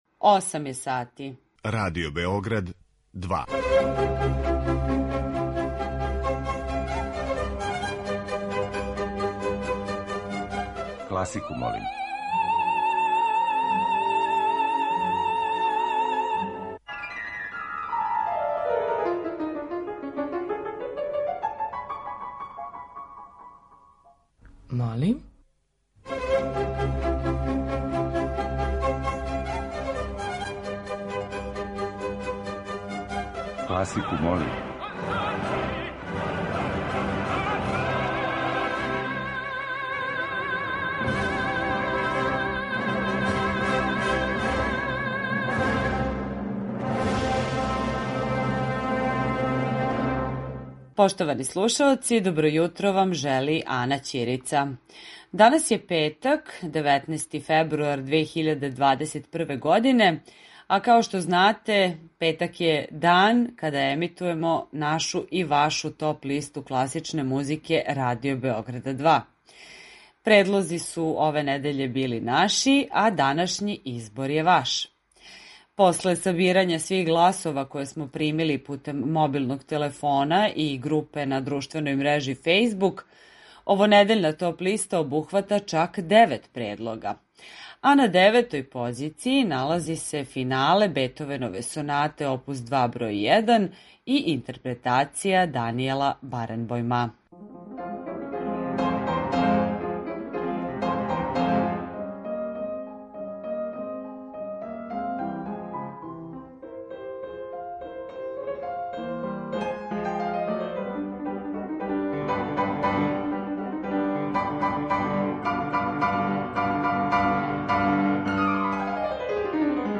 klasika.mp3